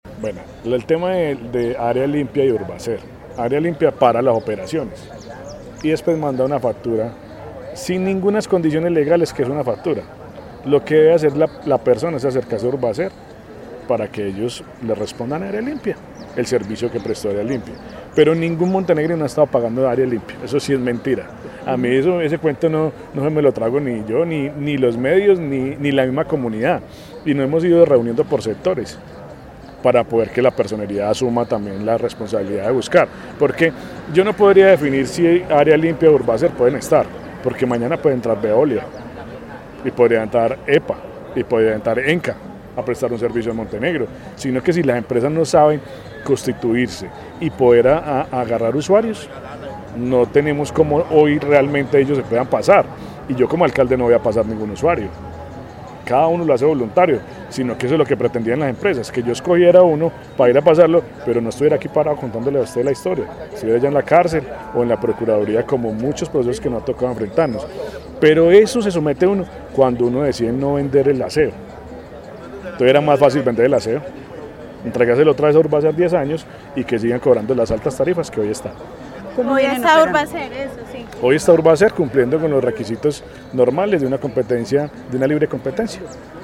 Alcalde de Montenegro